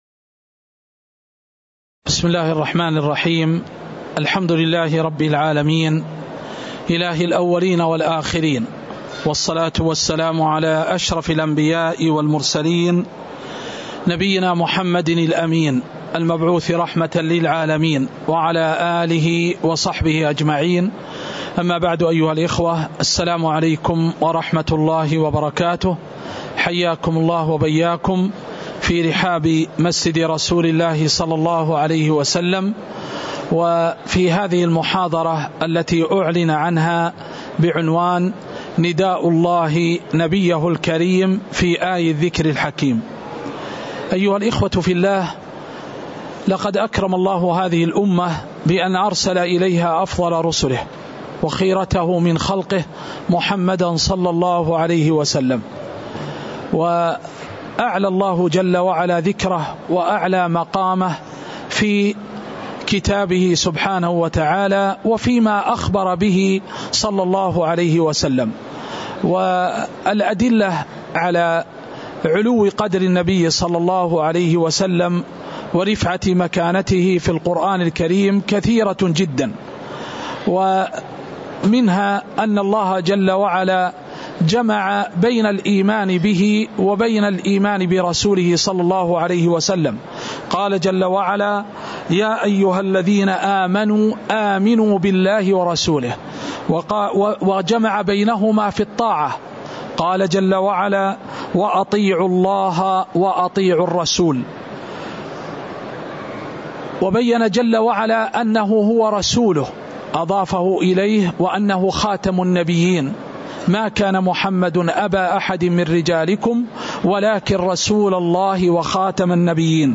تاريخ النشر ٢٧ محرم ١٤٤٥ هـ المكان: المسجد النبوي الشيخ